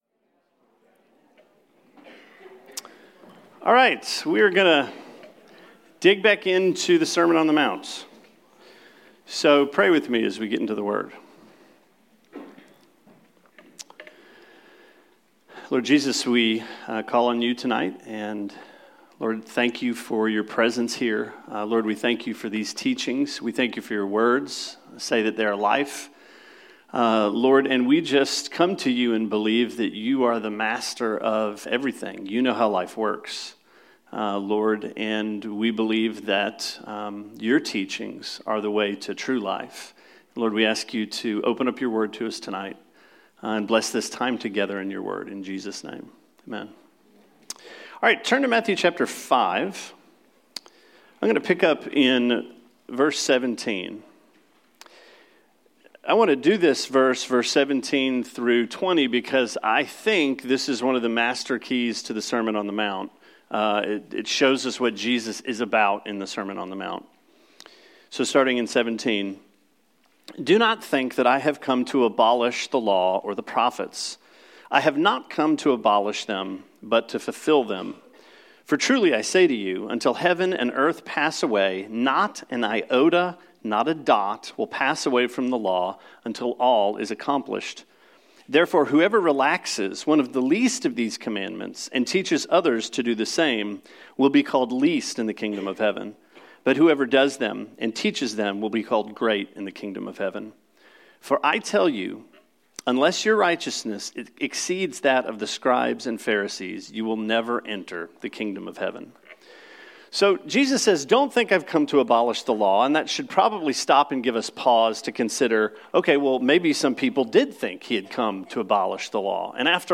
Sermon 01/31: Matthew 5:17-26